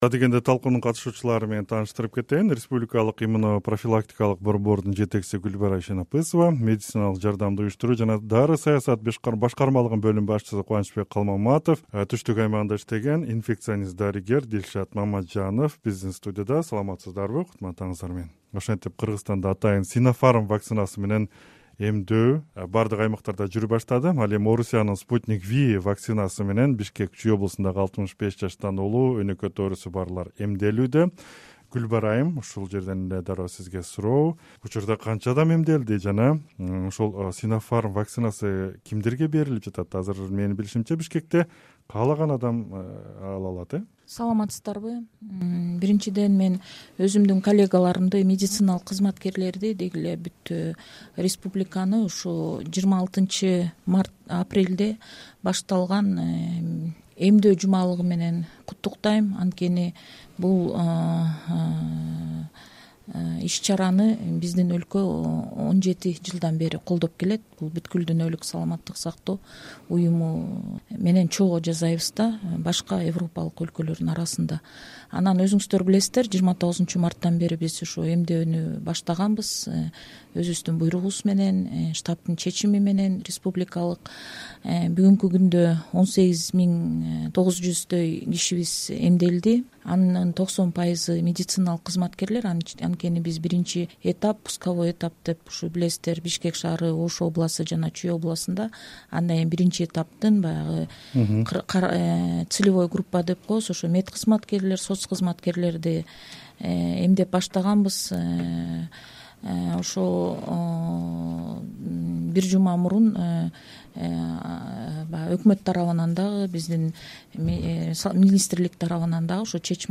"Арай көз чарай" талкуусу